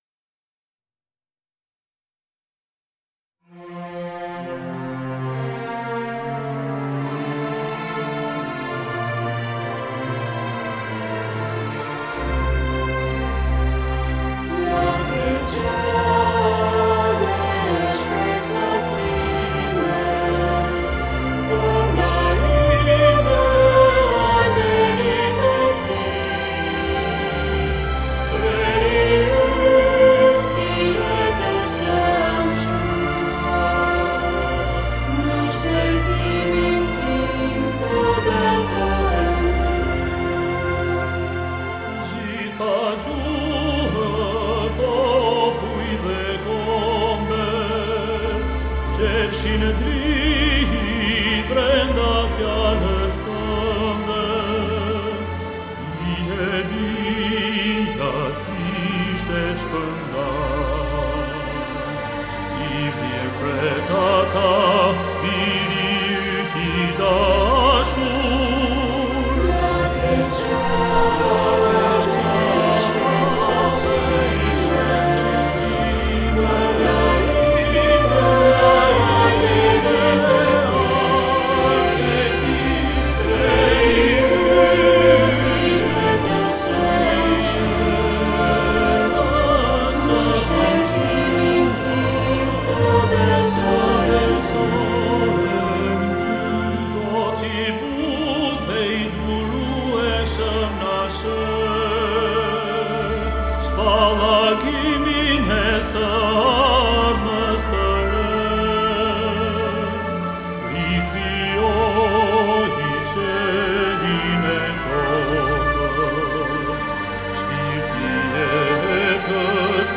Solist